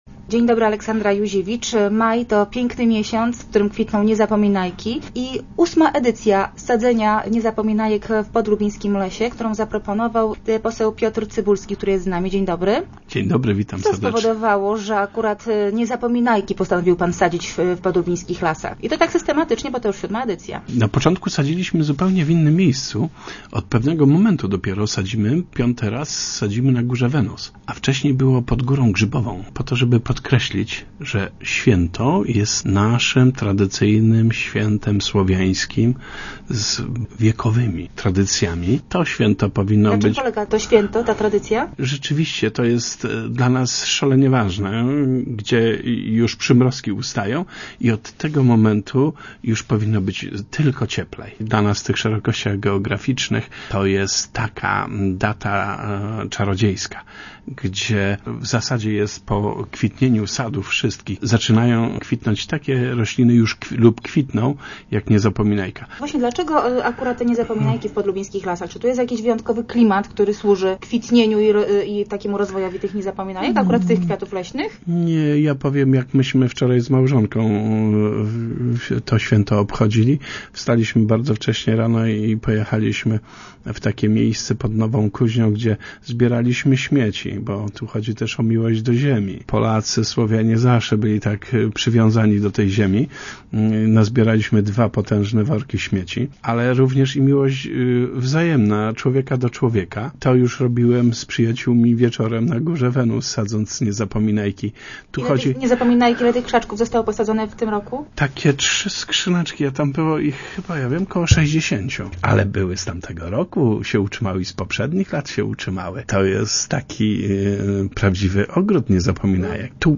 re_cybulski.jpgMajowa akcja sadzenia niezapominajek na Górze Wenus koło Rynarcic jest sposobem na przywołanie tradycji Święta Niezapominajki, uznanego za staropolskie Święto Miłości. O szczególnym znaczeniu tej leśnej rośliny w podlubińskich lasach opowiada poseł Piotr Cybulski, inicjator akcji.